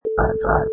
Sound sample: Pig Double Snort SFX 1
Double pig snort
Product Info: 48k 24bit Stereo
Category: Animals / Pigs
Relevant for: pigs, swine, hogs, snort, snorts, blow, laugh.
Try preview above (pink tone added for copyright).
Pig_Double_Snort_SFX_1.mp3